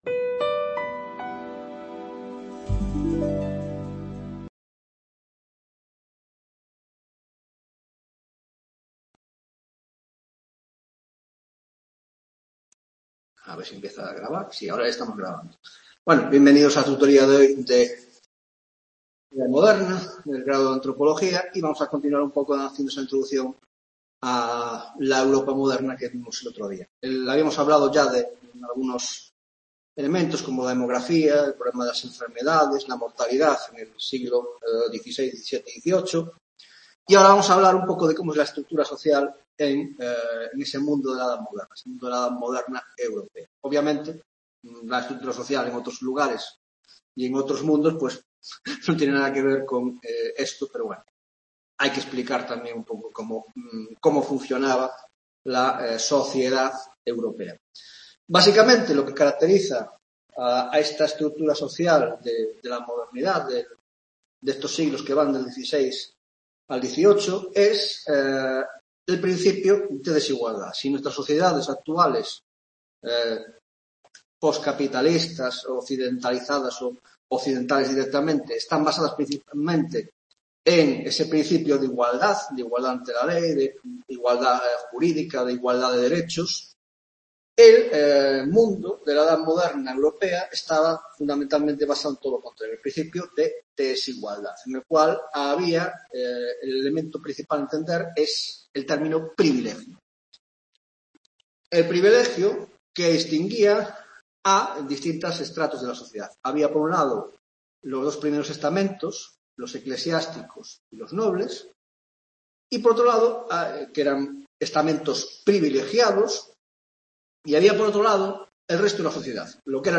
2ª Tutoria Historia Moderna (Grado de Antropologia Social y Cultural): 1) Estructura Social de la Europa Moderna - Sociedad Estamental y diversidad interna; 1.1) Privilegiados y no privilegiados; 1.2) Divisiones internas de los 3 Estamentos; 2) Introducción a la Economía de la Europa Moderna: 2.1) Capitalismo vs. Economía de subsistencia; 2.2) Evolución divergente del Este y el Occidente Europeo; 2.3) La Agricultura.